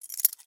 Pickup_Keys.wav